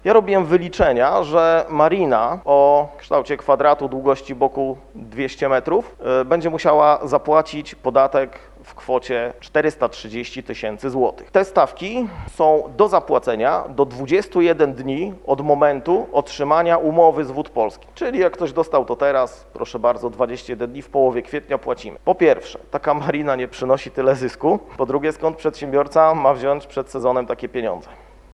na ostatniej sesji Rady Miejskiej w Giżycku